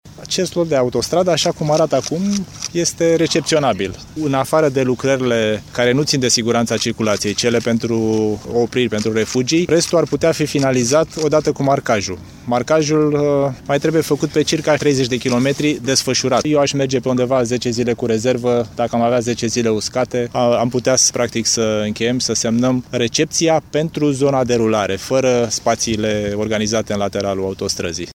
Lotul al doilea al autostrăzii Timişoara-Lugoj ar putea fi deschis circulaţiei cu şapte luni mai devreme decât termenul prevăzut în contract, a anunţat, la Timişoara, ministrul Transporturilor, Dan Marian Costescu. Oficialul a precizat că totul depinde de starea vremii:
02.-insert-costescu-inaugurare-autostrada.mp3